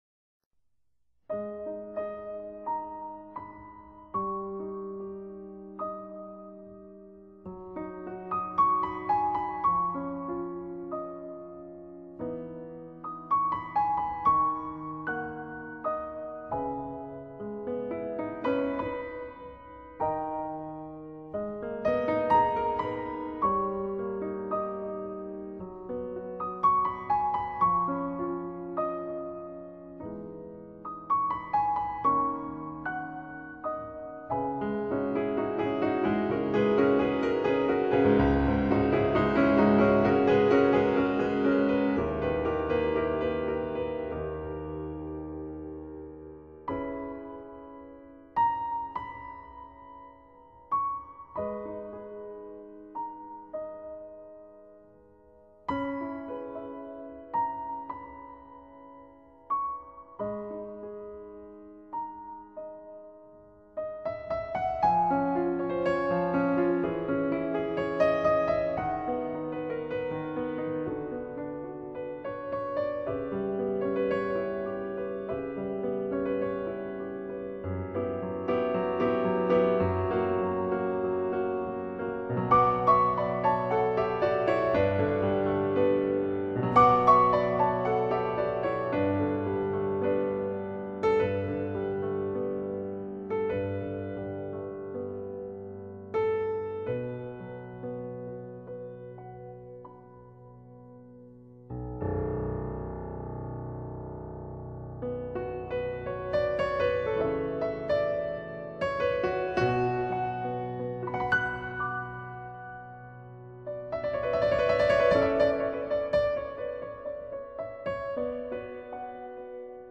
这是没有任何其他配器的钢琴独奏版本，尽显柔和本色。